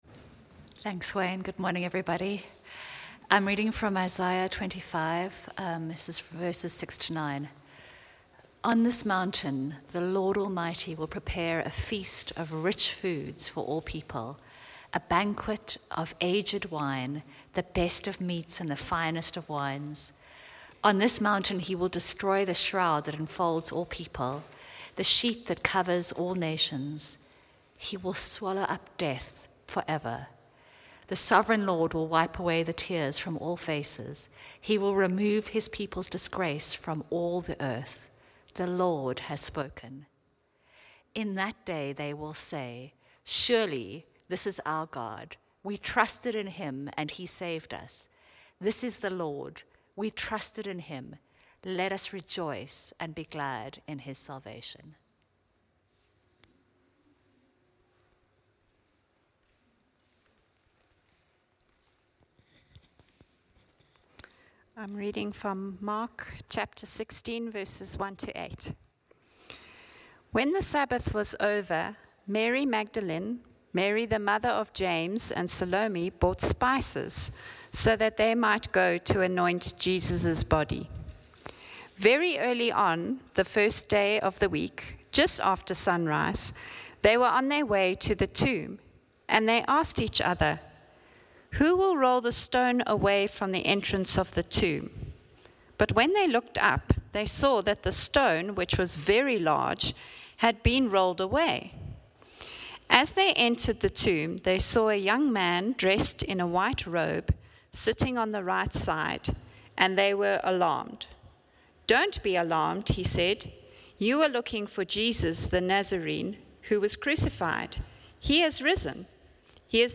From Hillside Vineyard Christian Fellowship, at Aan-Die-Berg Gemeente.